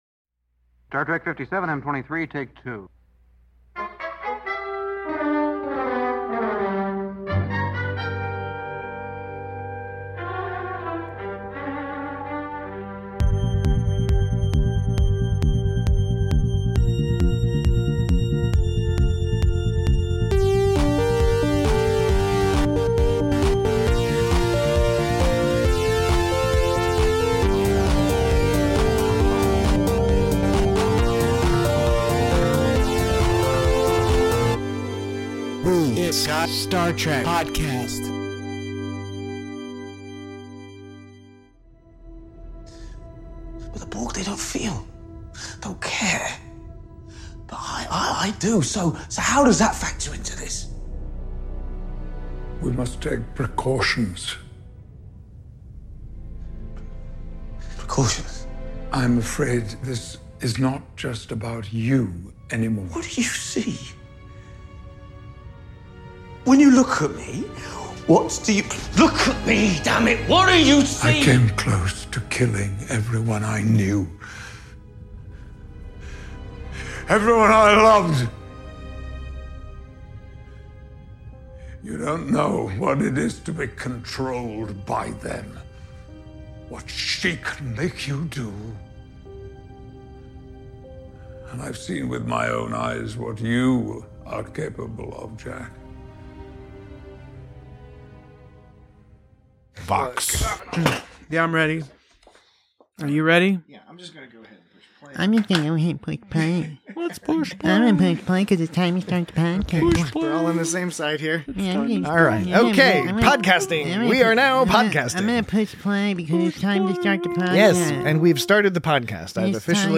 Join your Borg-wary hosts as they discuss the return of a frequently-returning antagonist, gripping and intense storytelling, and what the casts of Star Trek: Picard and The Goonies just might have in common.